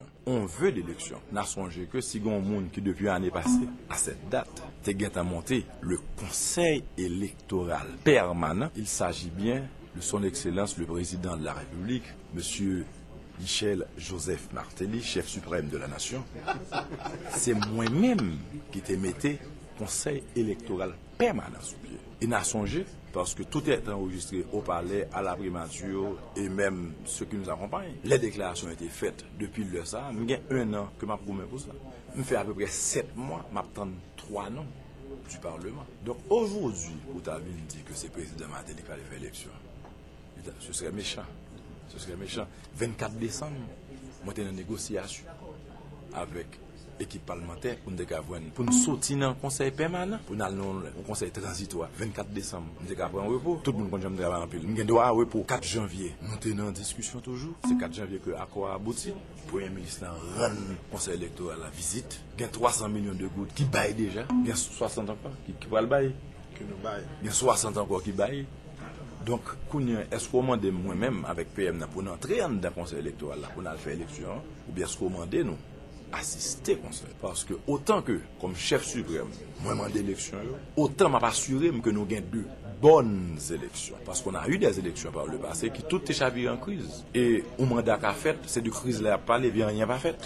Deklarasyon Prezidan Michel Martelly